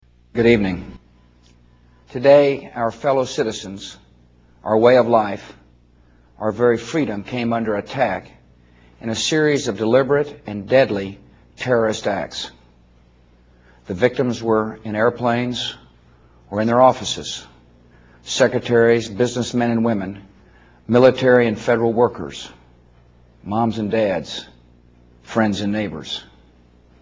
Bush 9-11 Speech 1
Tags: President George W. Bush 9-11 speech 9-11 attack George Bush speech Bush Speech Sept. 11 2001